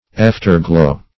After-glow \Aft"er-glow\, n.